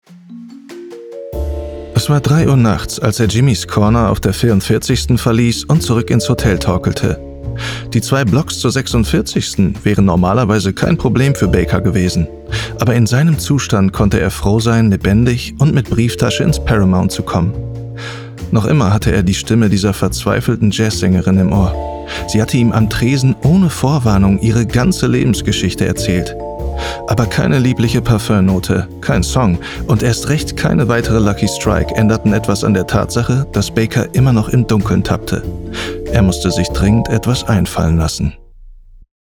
plakativ, markant, sehr variabel
Mittel minus (25-45)
Ruhrgebiet
Audiobook (Hörbuch)